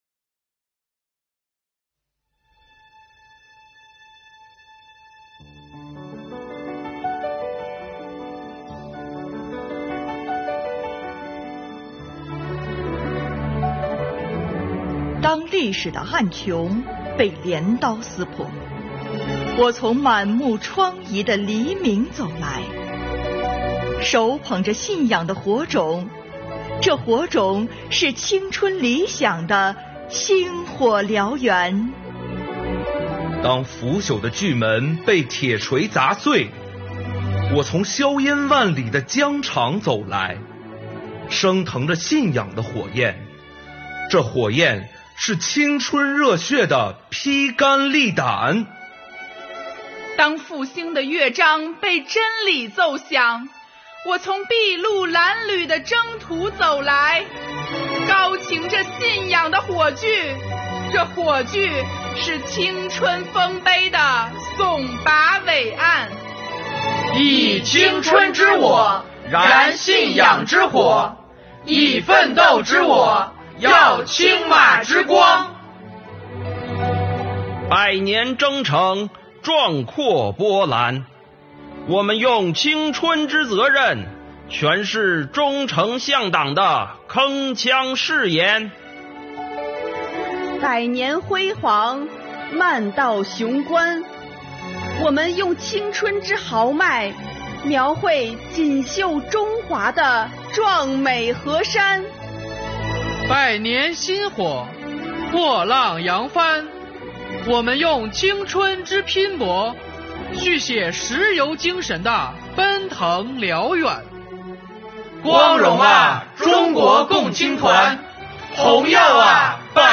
诵读征集活动优秀作品